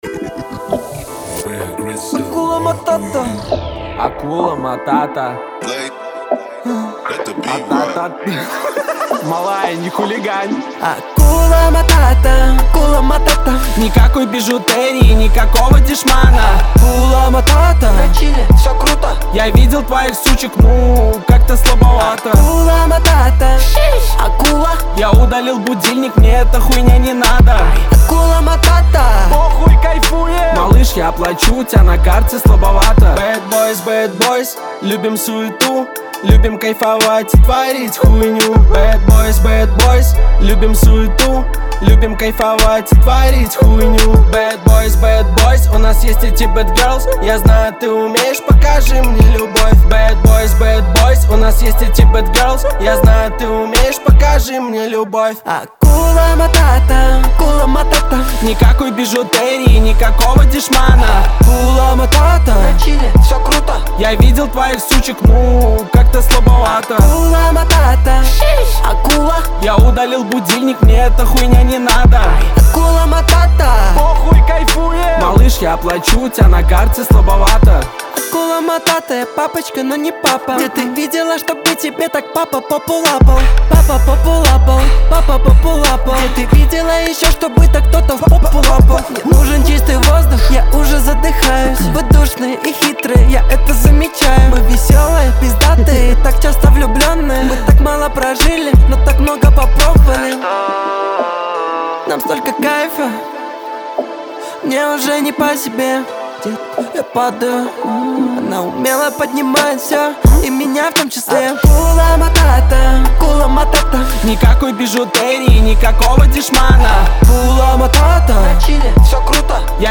Поп музыка, Рэп